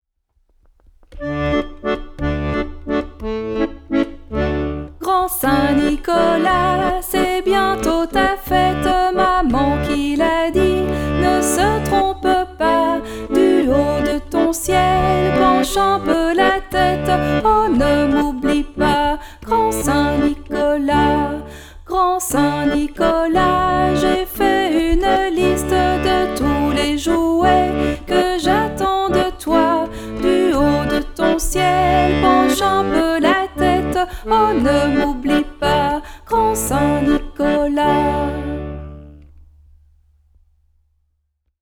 chant
accordéon